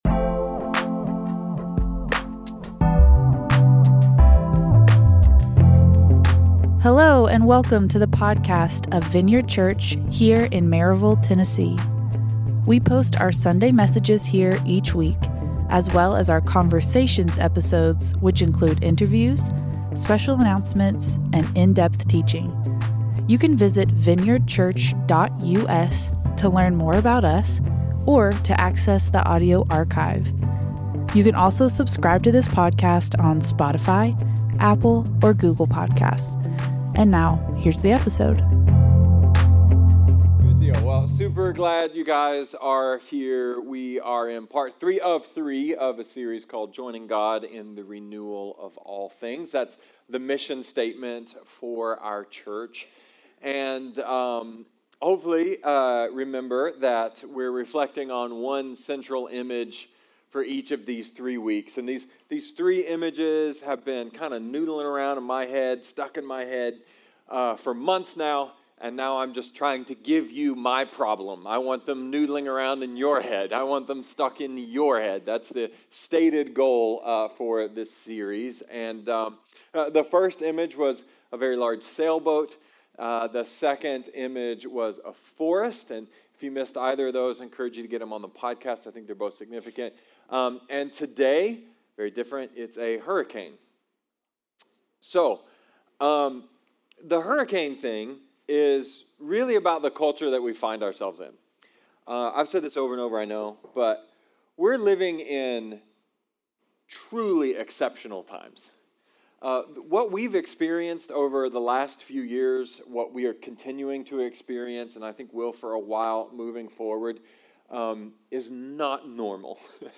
A sermon about chaos and polarization and the miraculous possibility for peace and love in the midst of it all.